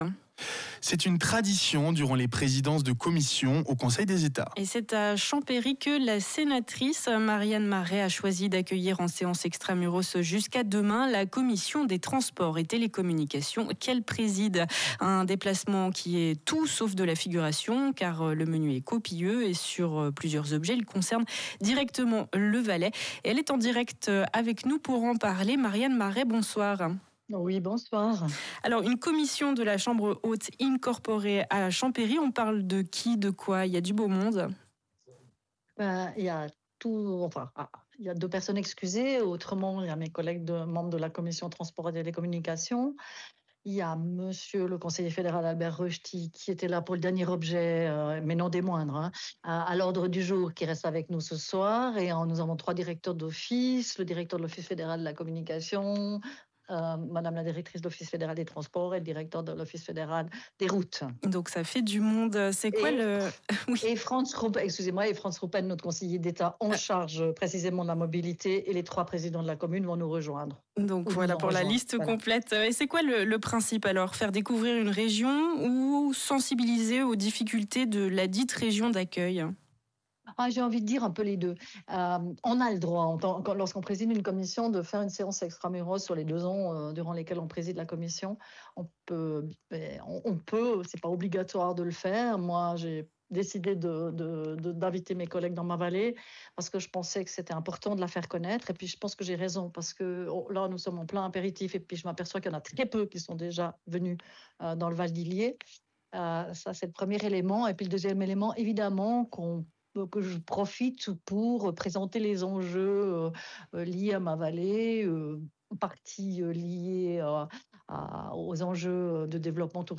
Le lundi, j’ai eu le plaisir de participer en direct au Journal du soir de Rhône FM pour parler de ce moment particulier.
Mon interview pour Rhône FM Toute mon actualité au Conseil des États